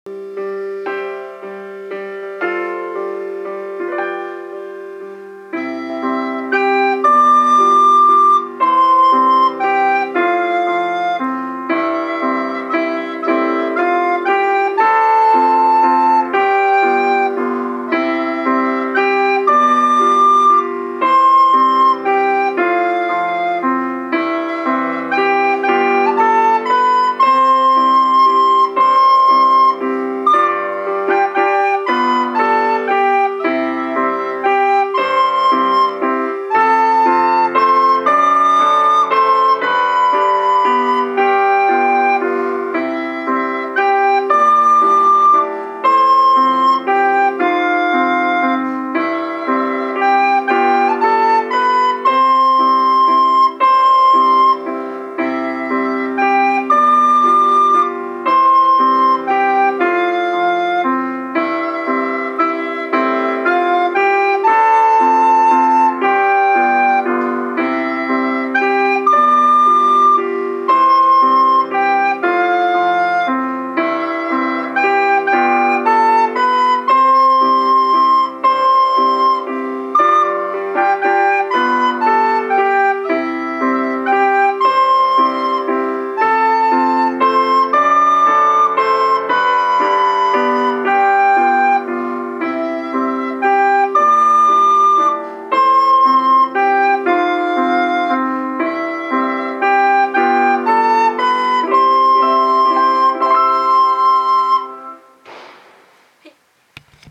今回の課題に「エーデルワイス」の楽譜を入れています。リコーダーでゆっくり音を出して吹いてみましょう。